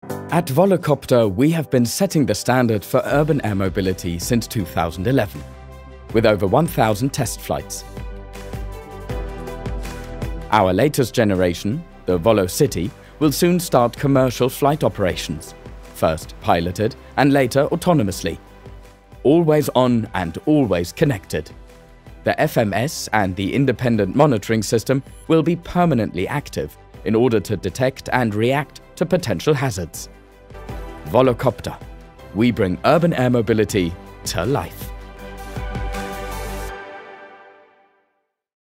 Seine Stimme ist warm, jung, dynamisch, authentisch, natürlich.
Sprechprobe: Industrie (Muttersprache):
His voice is warm, young, upbeat, credible, natural.